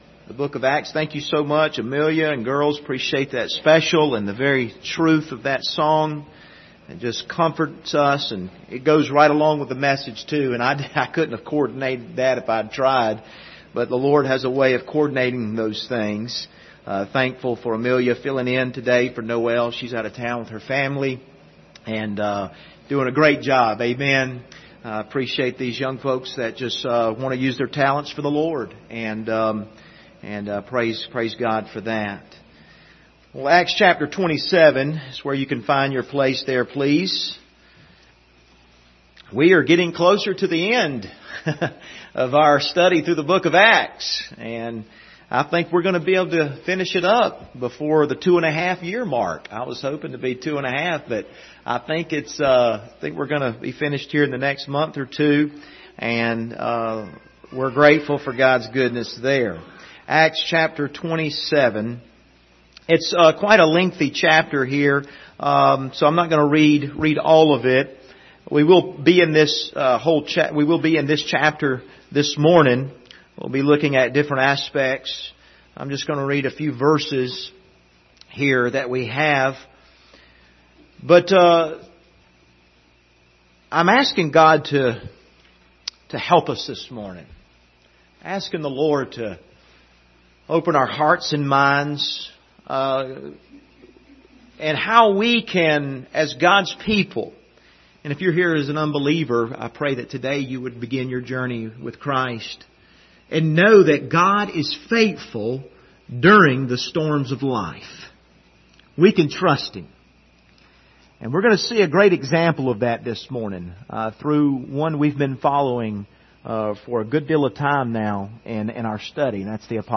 Passage: Acts 27:1-10 Service Type: Sunday Morning